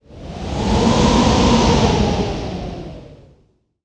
chilling-wind-noise